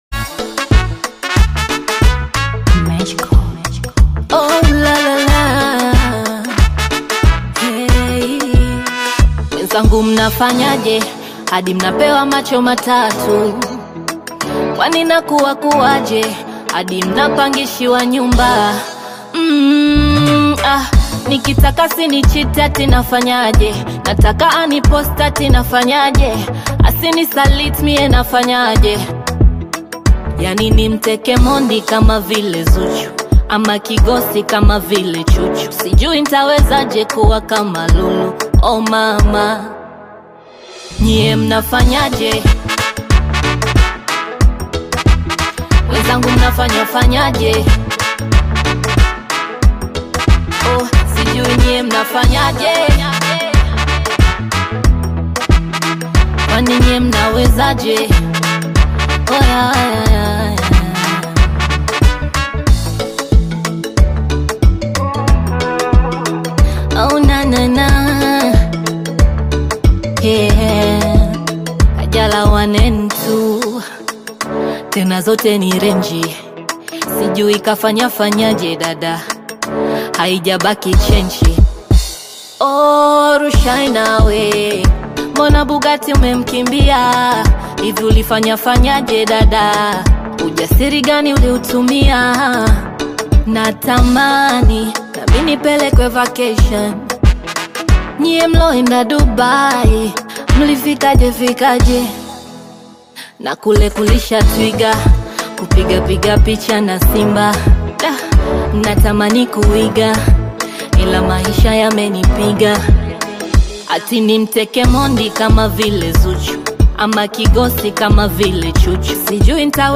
Tanzanian bongo flava artist, singer and songwriter
African Music